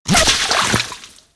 impactwatersmall01.wav